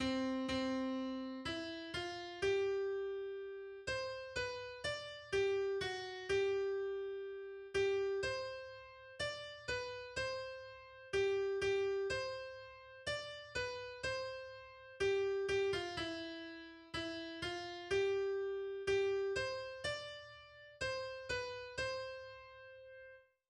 Weihnachtslied aus dem 17. Jahrhundert